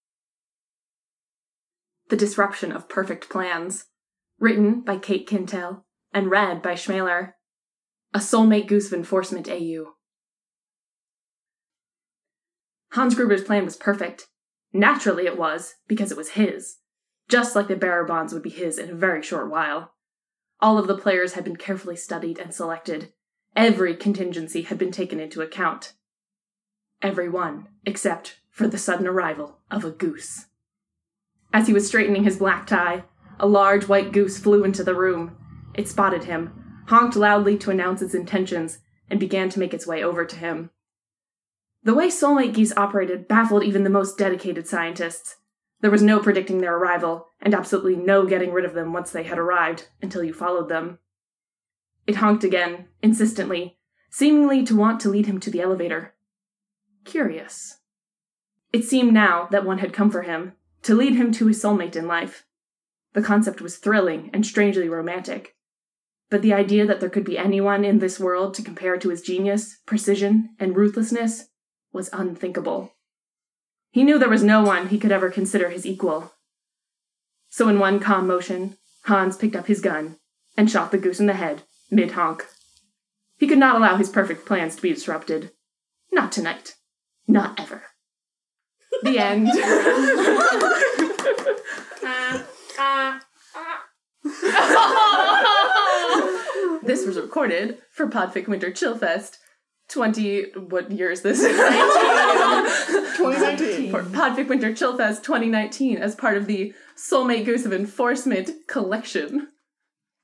[Podfic Version]
Performed live at Podfic Winter Chillfest 2019!